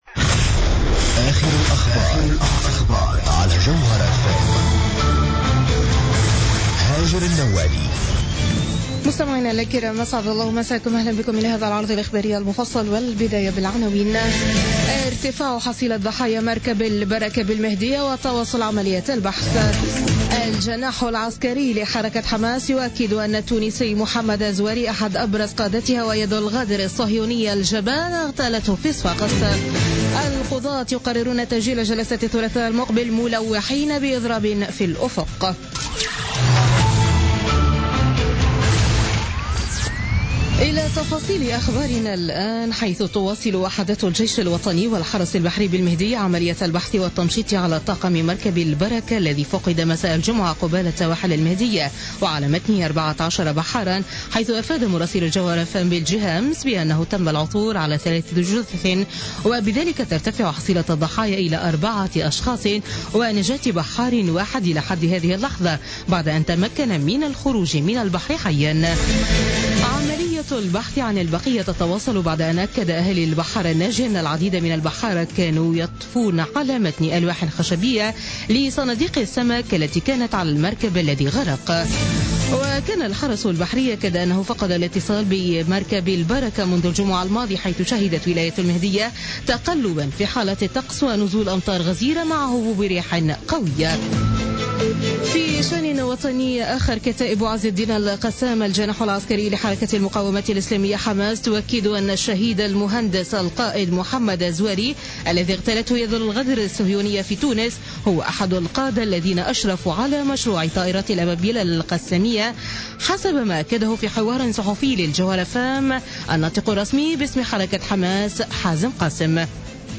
نشرة أخبار منتصف الليل ليوم الأحد 18 ديسمبر 2016